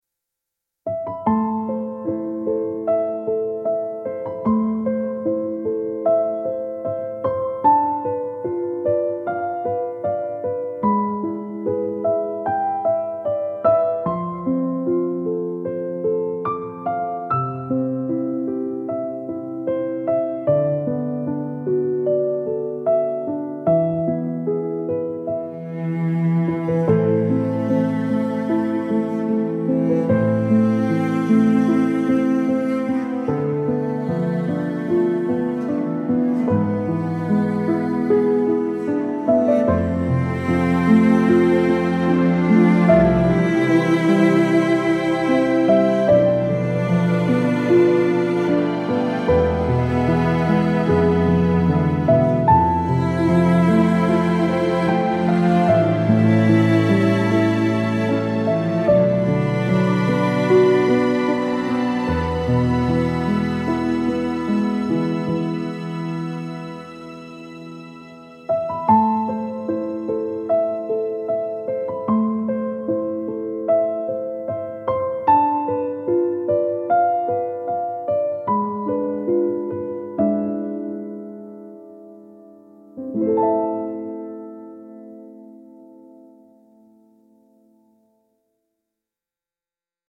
vintage inspired love theme with gentle piano and nostalgic atmosphere